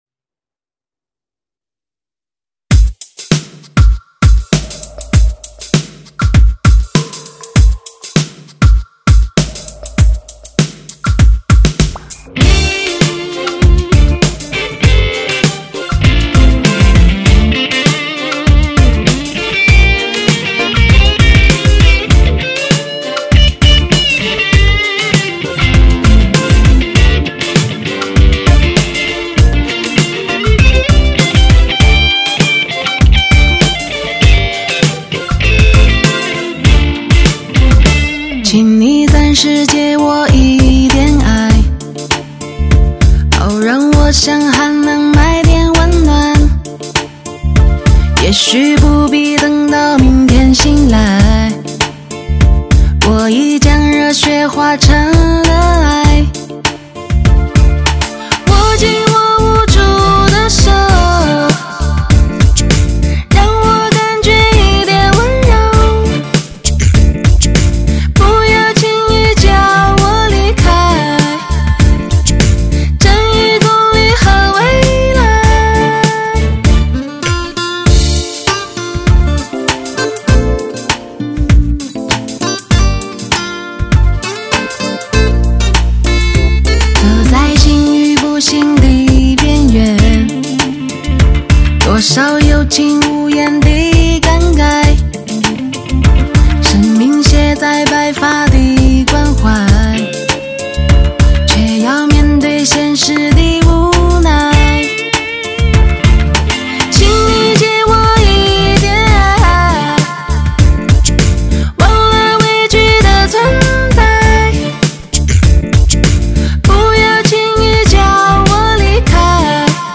最自然的发烧女声，穿透心灵，再次征服你的耳朵；
聆听独特的个人气质和醇厚磁性的声线，让挑剔金耳再次与诱惑邂逅相遇。